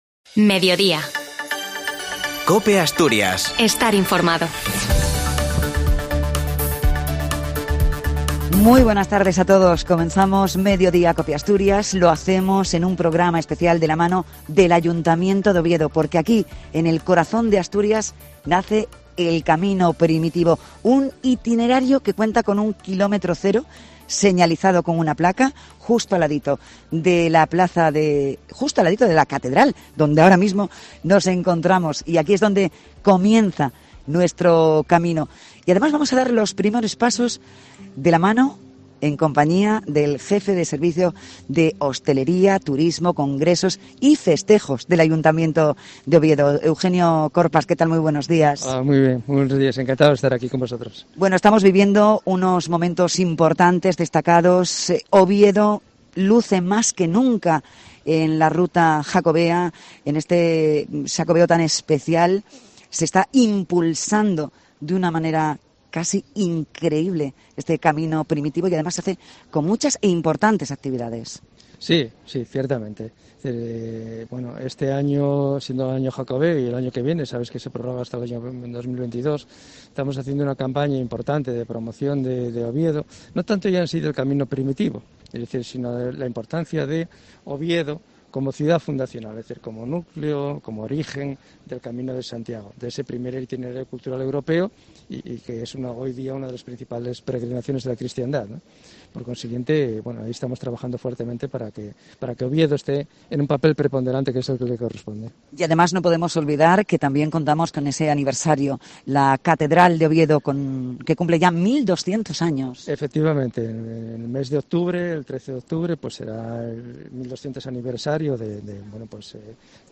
desde la Catedral de Oviedo, el kilómetro cero del Camino Primitivo. Desde ese punto, inició el primer peregrino, Alfonso II el Casto, su trayecto hacia Santiago.